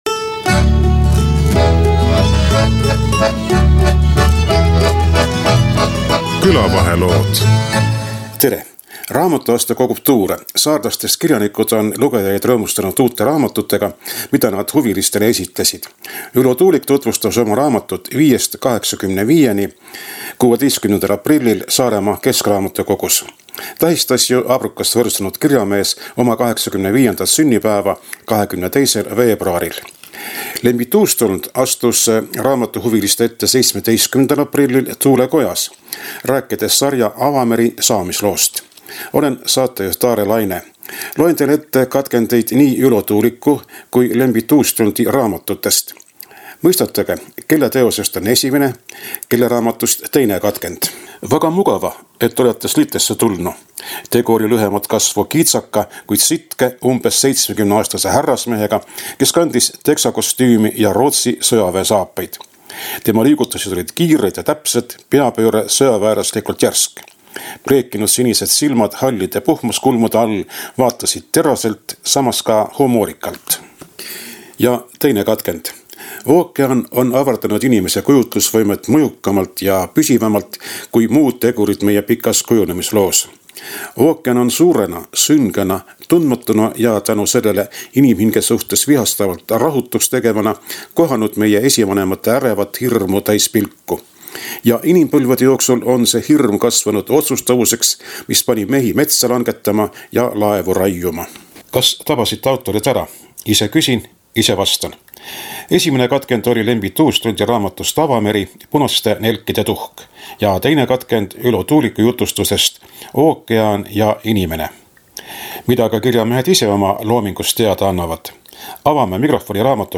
22. veebruaril 85. aastaseks saanud kirjanik Ülo Tuulik esitles raamatusõpradele oma uut raamatut ´´Viiest kaheksakümneviieni´´ 16. aprillil Saaremaa keskraamatukogu lugemissaalis. Järgmisel päeval astus Thule kojas lugejate ette kaptenivormis kirjanik Lembit Uustulnd, tutvustades sarja Avameri saamislugu.
intervjueerides nii kirjanikke kui ka raamatusõpru.